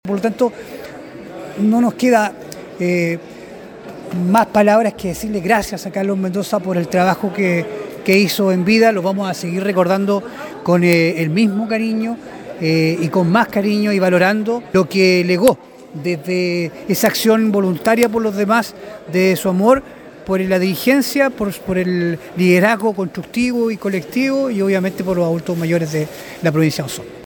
el Consejero Regional por la Provincia de Osorno, Francisco Reyes